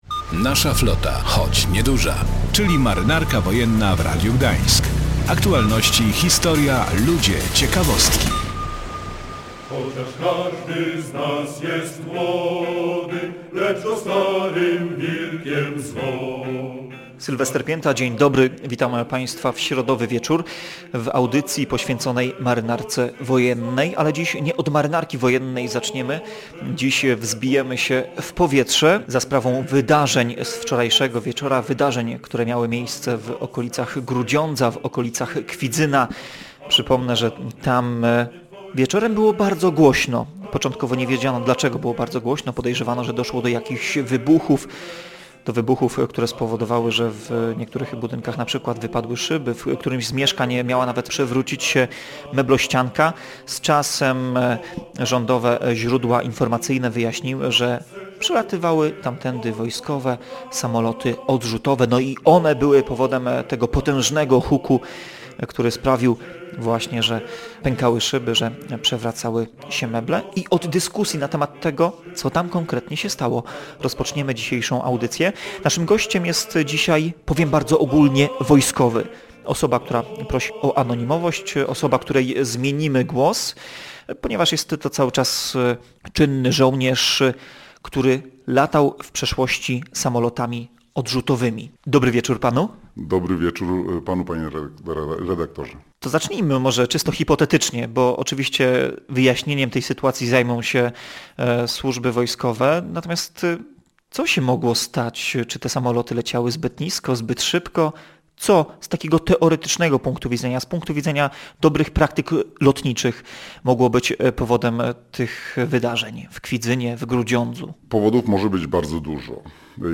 I o tym właśnie rozmawialiśmy z proszącym o anonimowość byłym pilotem wojskowych samolotów odrzutowych.